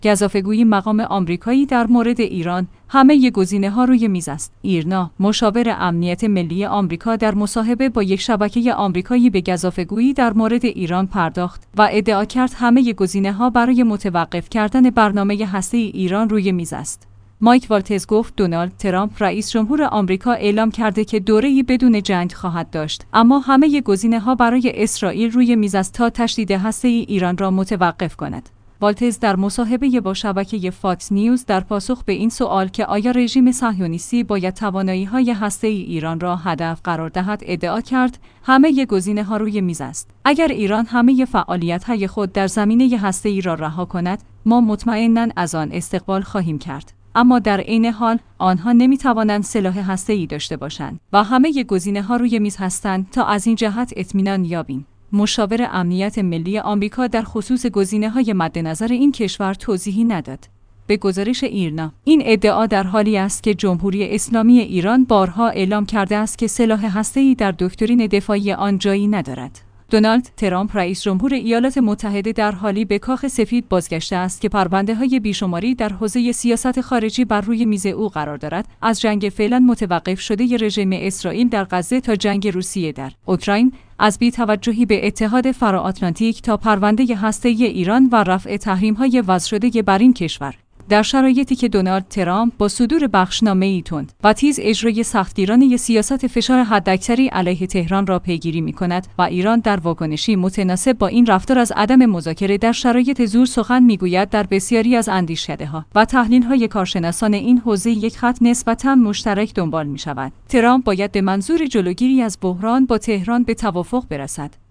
ایرنا/ مشاور امنیت ملی آمریکا در مصاحبه با یک شبکه آمریکایی به گزافه گویی در مورد ایران پرداخت و ادعا کرد همه گزینه ها برای متوقف کردن برنامه هسته ای ایران روی میز است.